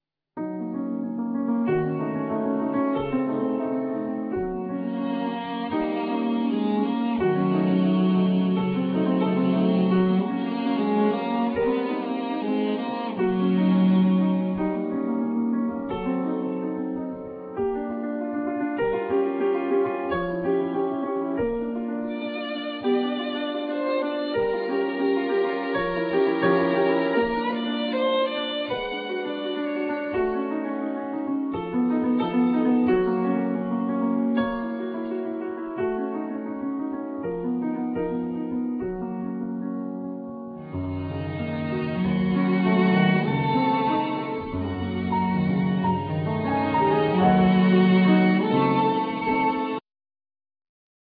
Violin
Cello
Piano